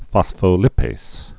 (fŏsfō-lĭpās, -līpās)